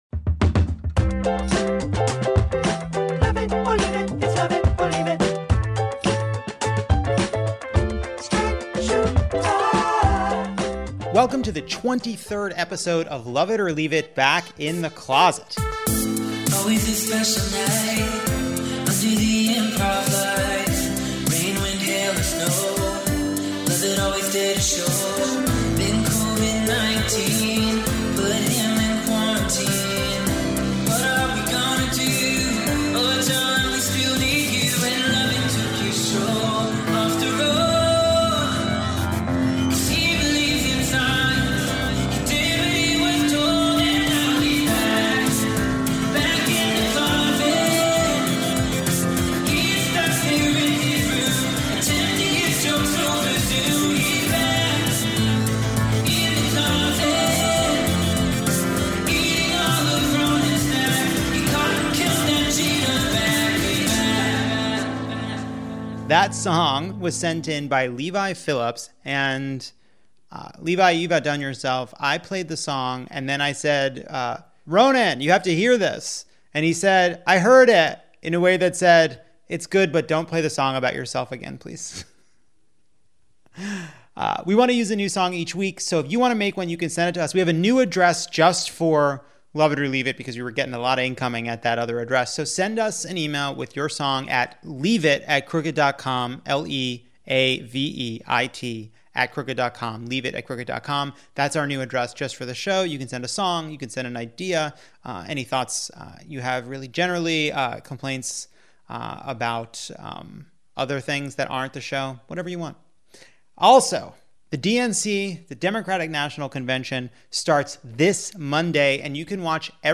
Sasheer Zamata is here for the monologue. Alyssa Mastromonaco talks about the VP roll out. Ben Rhodes walks us through a week of big developments in foreign policy. And we quiz a listener (and math PhD!) on the different economic stimulus plans.